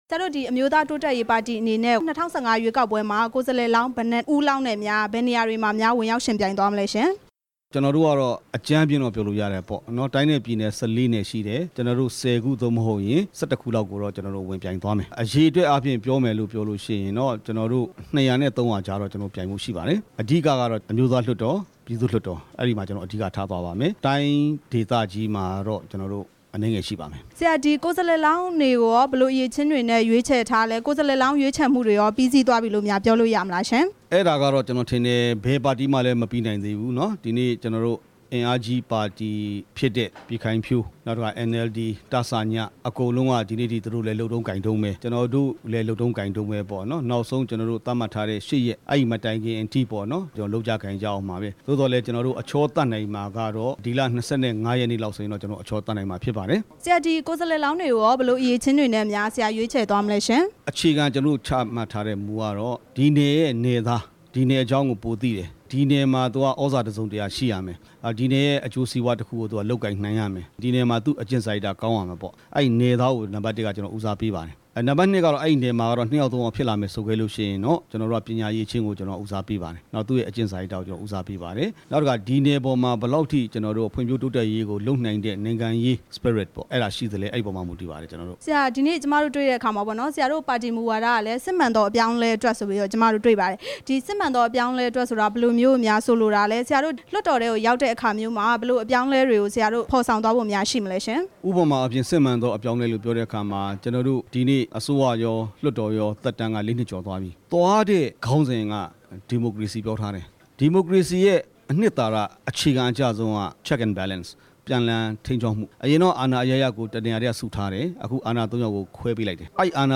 အမျိုးသားတိုးတက်ရေးပါတီ ဥက္ကဌ ဒေါက်တာနေဇင်လတ် နဲ့ မေးမြန်းချက်